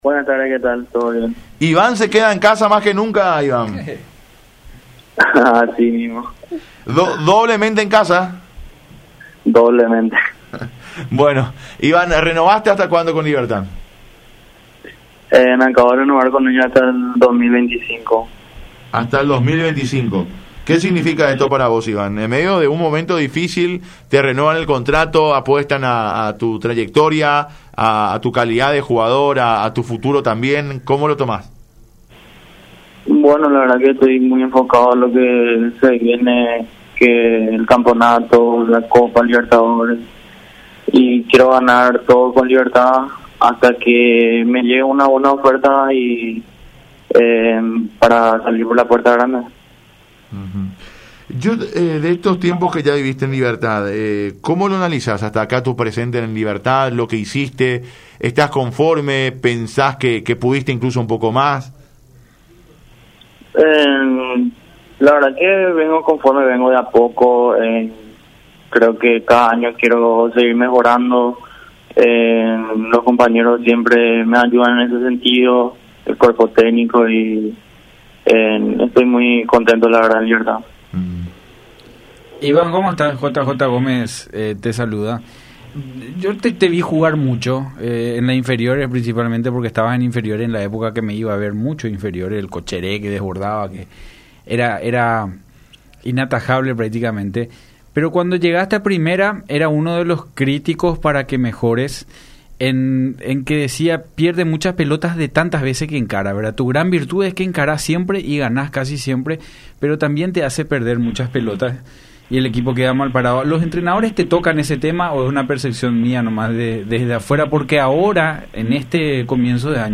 en diálogo con Unión FC.